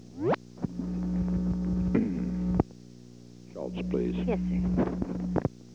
Location: White House Telephone
The President talked with the White House operator.